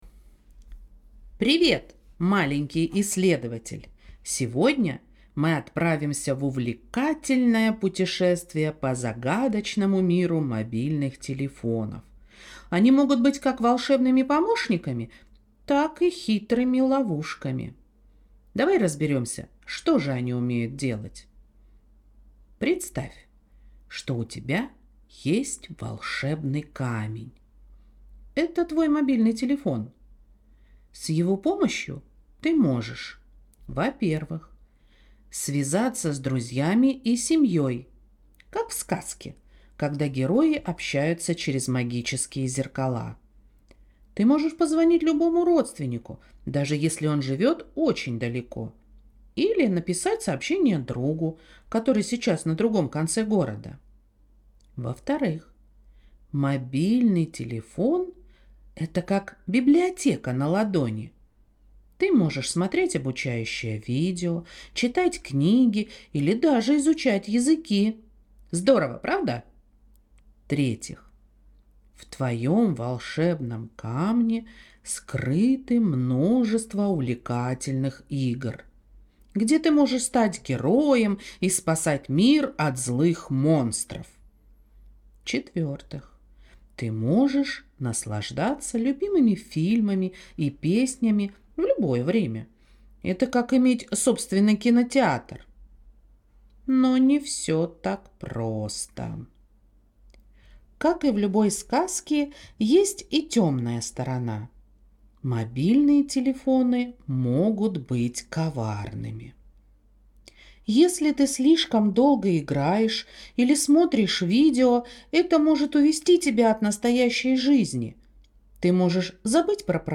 будет рассказывать детям в увлекательной манере про пользу и вред мобильного телефона.